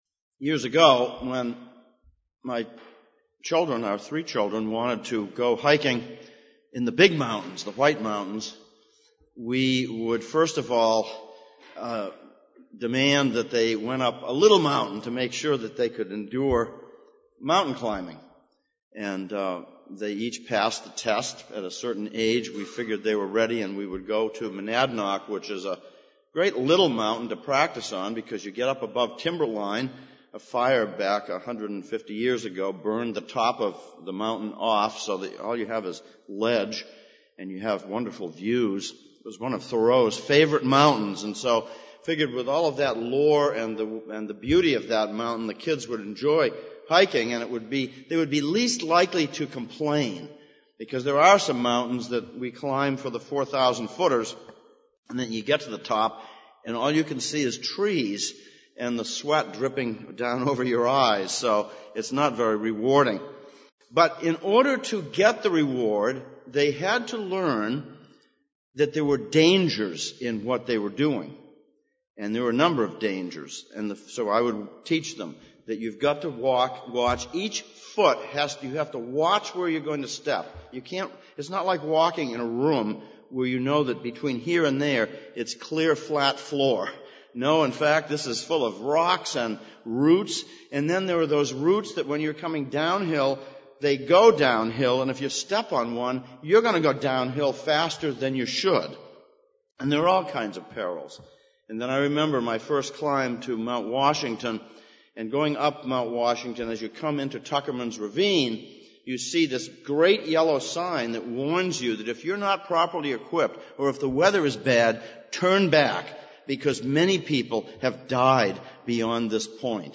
Hebrews Passage: Hebrews 3:7-19, Psalm 95:1-11 Service Type: Sunday Morning « Faith in Affliction John Bunyan